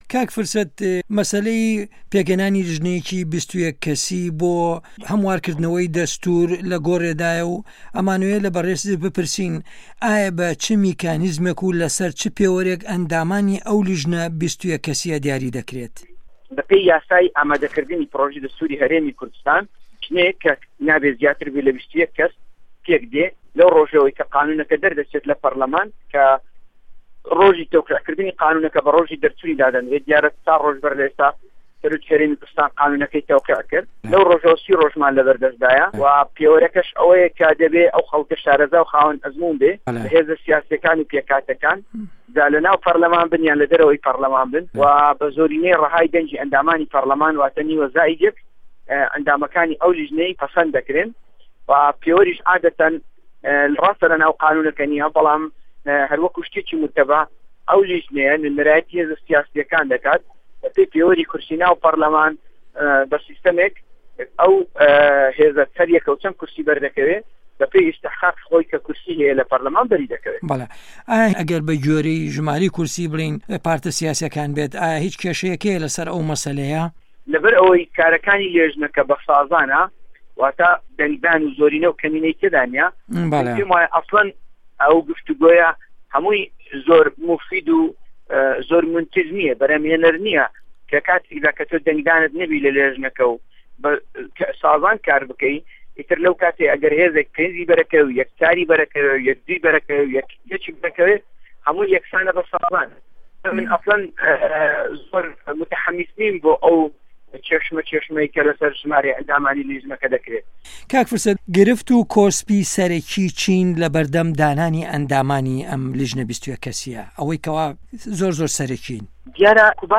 وتووێژ له‌گه‌ڵ فرسه‌ت سۆفی